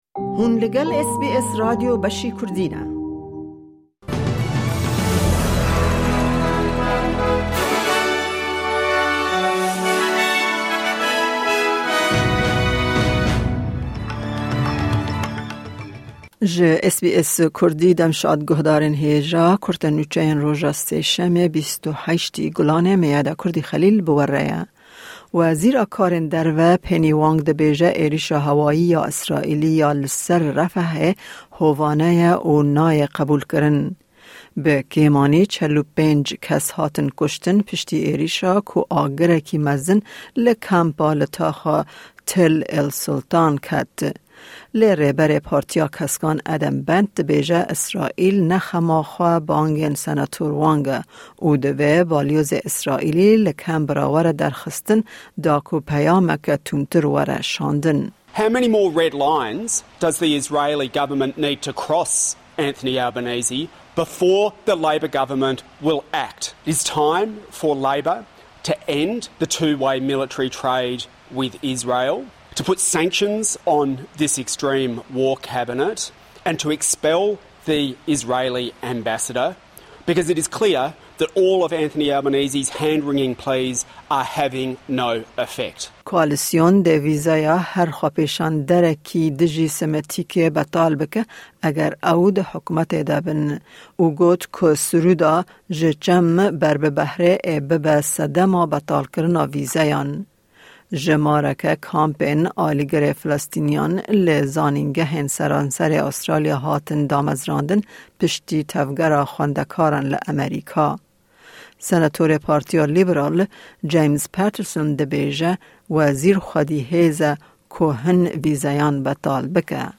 Kurte Nûçeyên roja Sêşemê 28î Gulana 2024